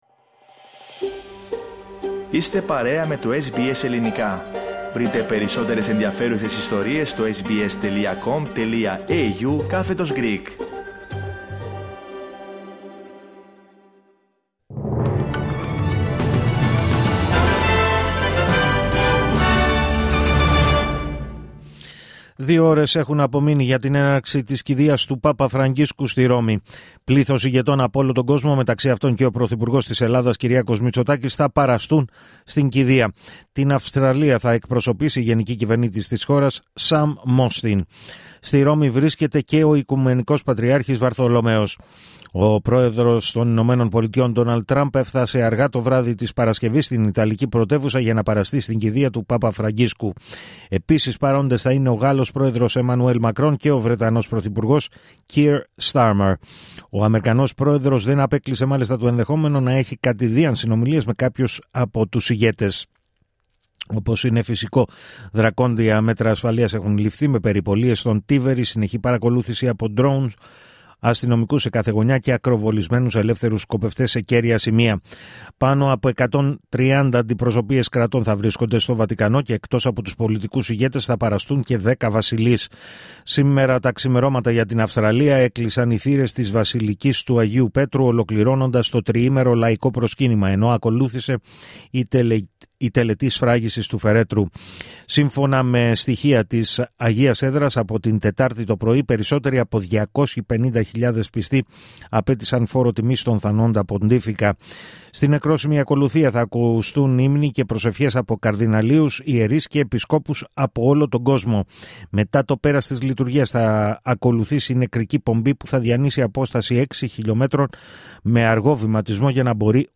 Δελτίο Ειδήσεων Σάββατο 26 Απριλίου 2025